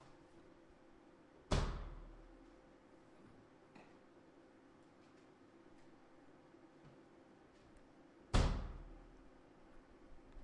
car door
描述：A car door opening and closing
标签： door slam car
声道立体声